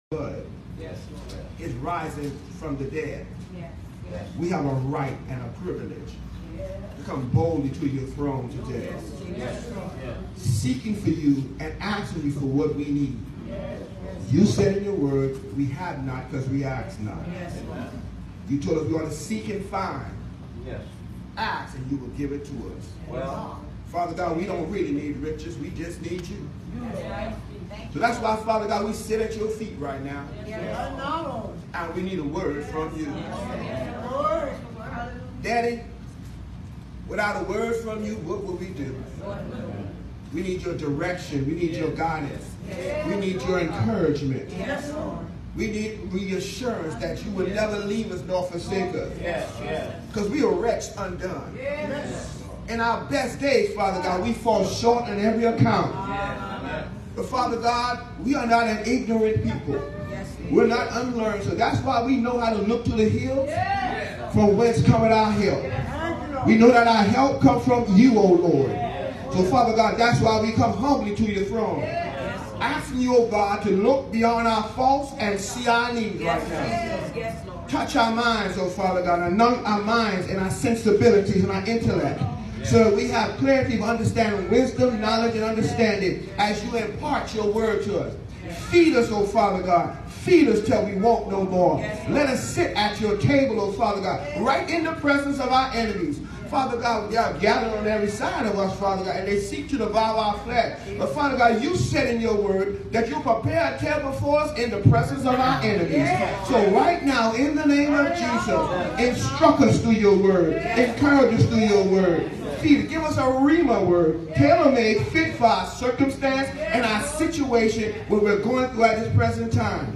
SEPTEMBER 1, 2019 SUNDAY 11:00am NEW JERUSALEM MB CHURCH CORINTHIANS 13:11 THE MESSAGE: “IT’S TIME TO GROW UP”